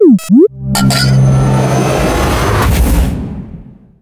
CargoBayFilled.wav